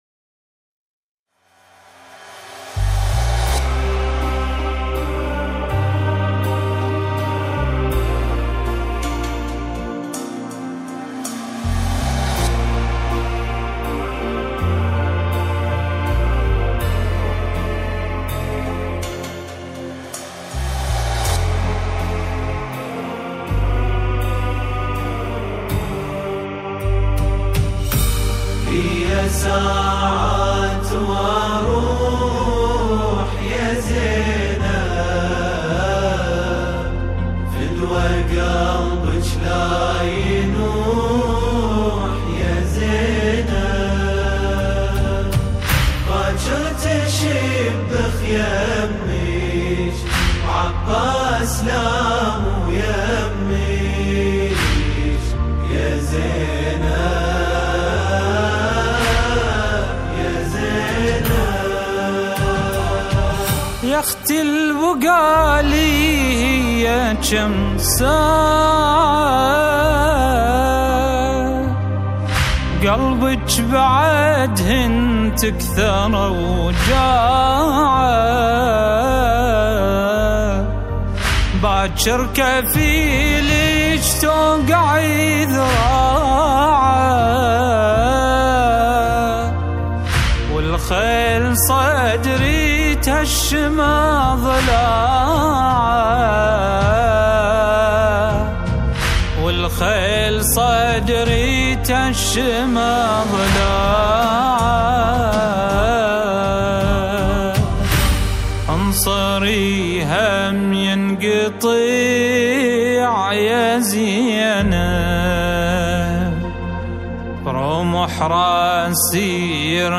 🎛الهندسة الصوتية والتوزيع